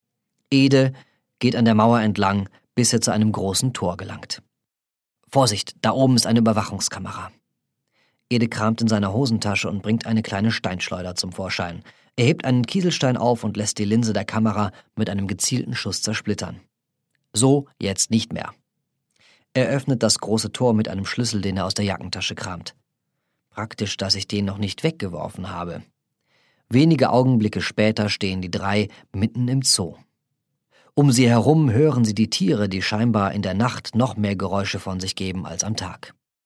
Der_Fall_Marlar-Hoerprobe.mp3